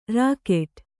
♪ rākeṭ